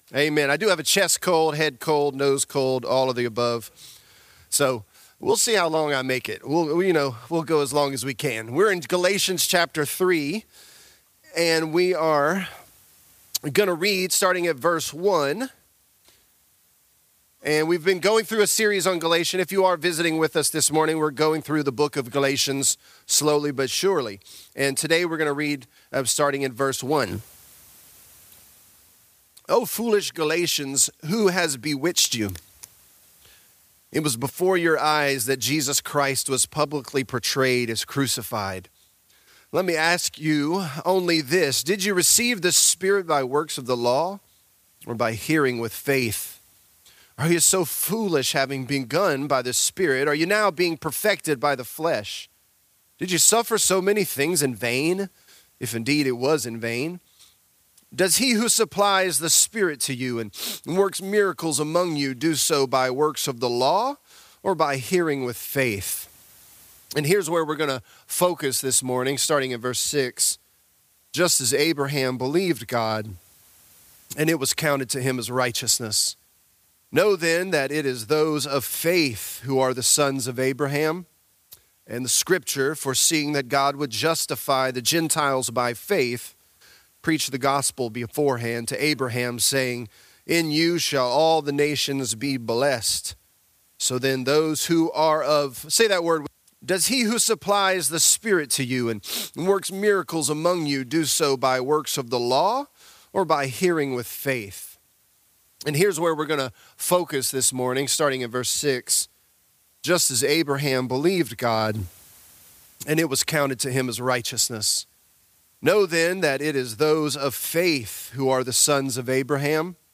Galatians: True Jews | Lafayette - Sermon (Galatians 3)